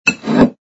sfx_pick_up_bottle02.wav